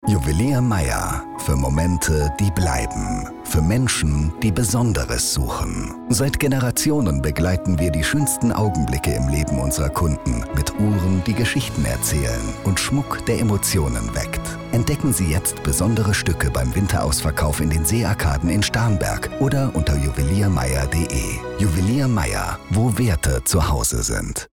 GNG_Spot_Juwelier-Mayer-M1.mp3